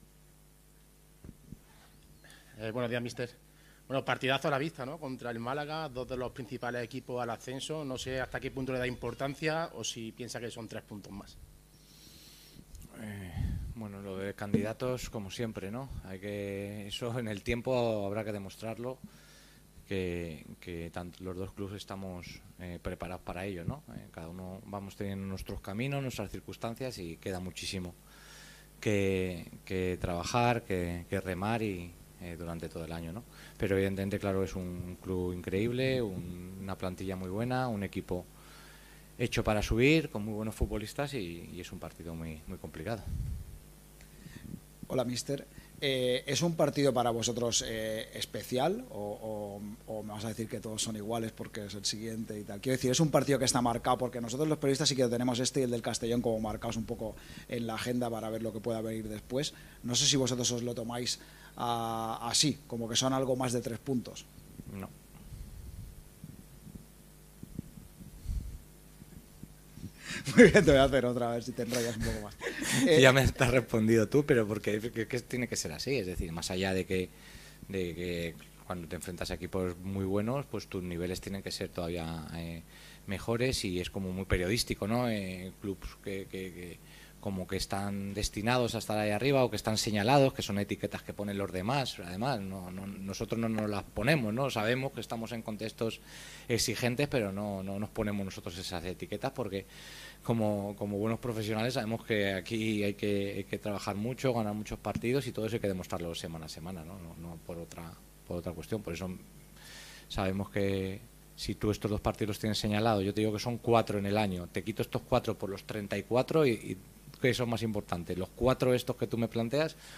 Este viernes fue el turno para las comparecencias de previa.